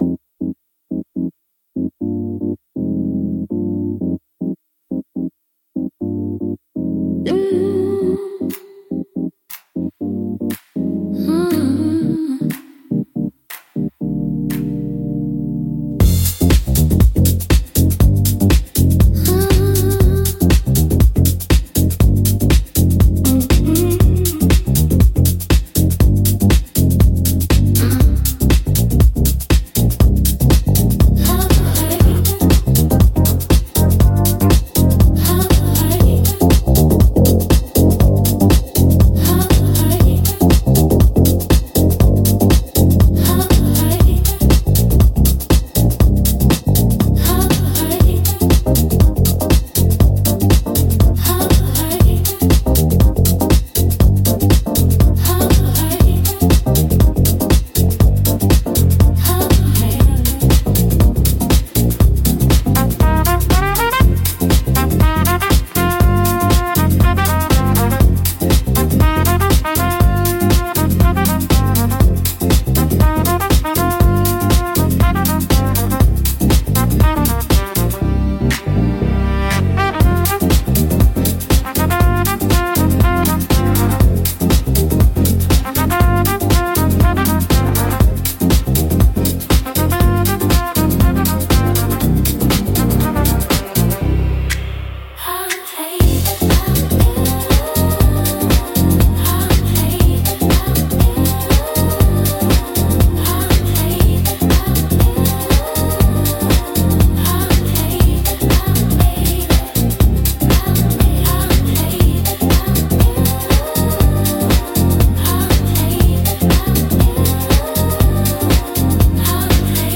特に、ポジティブで元気な印象を与えたい時に適しています。